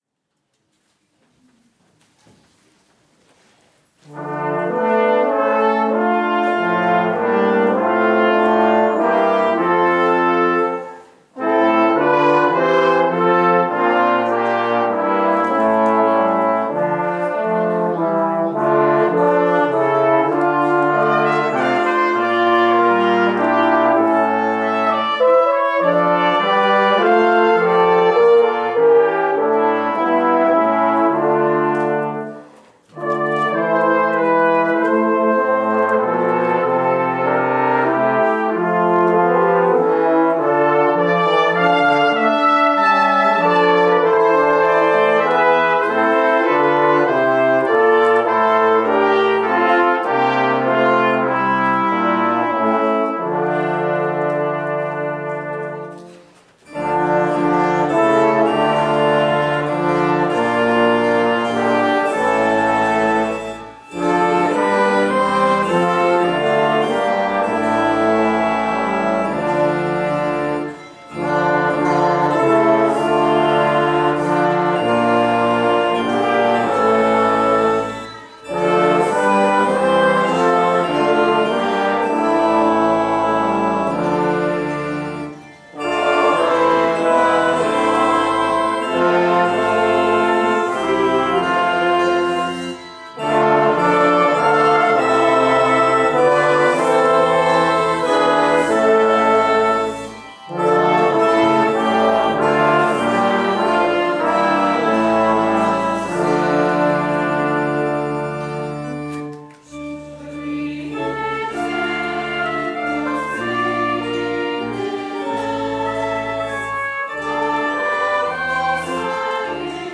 We celebrated Reformation Sunday on Oct. 27th and our Director of Music
The opening hymn, Psalmody, sermon hymn, and closing hymn include congregation singing.
Opening Hymn Stanzas 1-3